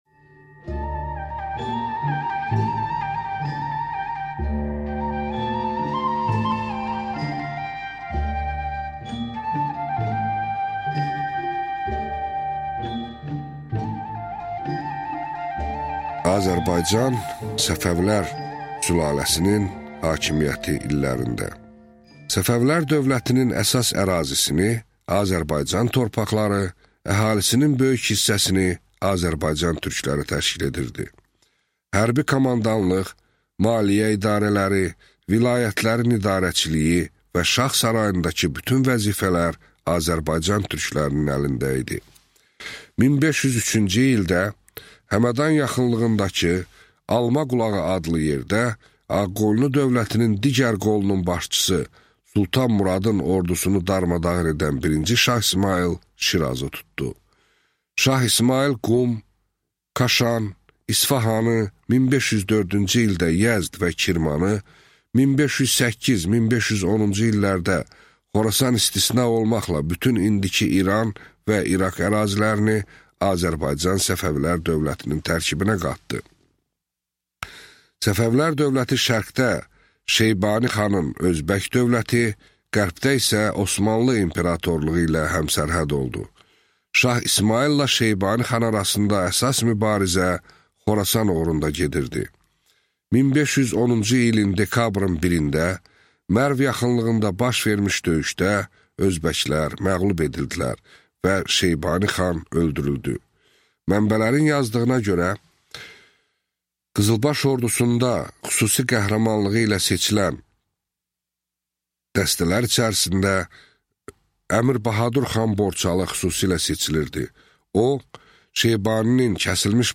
Аудиокнига Səfəvilər dövləti | Библиотека аудиокниг